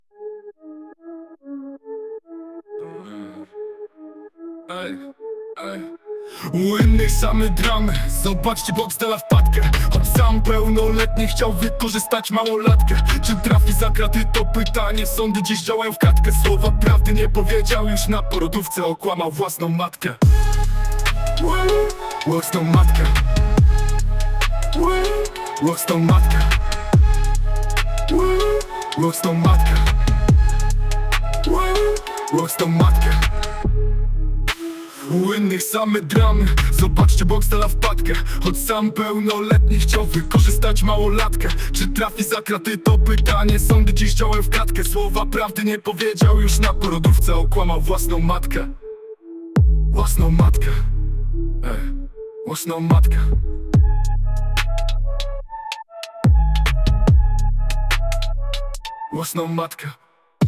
To AI jest zabawne ;D poprosiłem by stworzyło piosenke o Boxdelu, i przedstawiłem tego delikwenta :D MP3 do odsłuchania tekst: U innych same dramy, zobaczcie Boxdela wpadkę Choć sam pełnoletni chciał wykorzystać małolatkę Pokaż całość